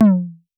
Index of /musicradar/retro-drum-machine-samples/Drums Hits/WEM Copicat
RDM_Copicat_SY1-Tom01.wav